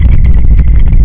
pumpkin_low.ogg